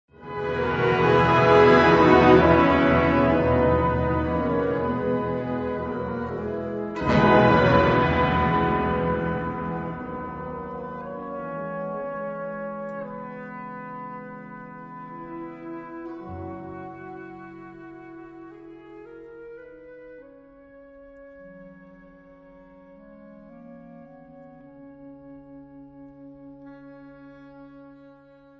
Blasorchester; symphonisches Blasorchester